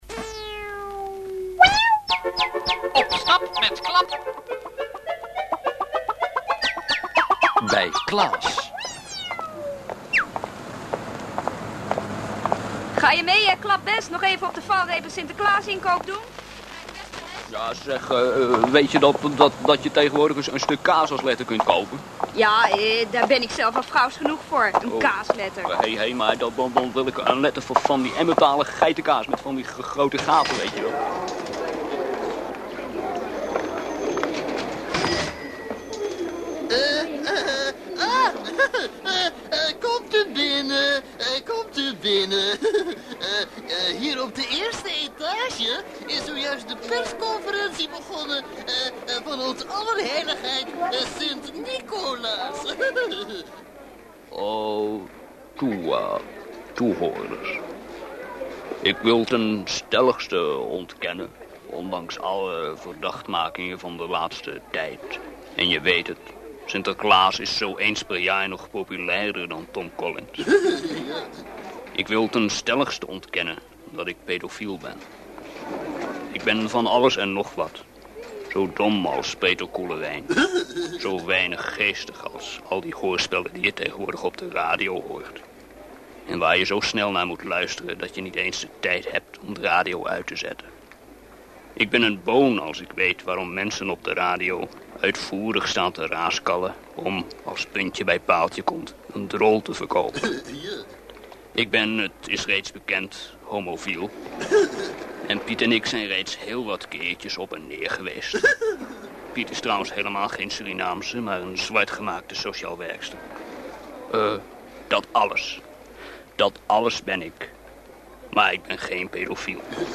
Ik vond in een oude doos een paar tapes met mono opnames van crappy kwaliteit.